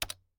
pause-back-hover.ogg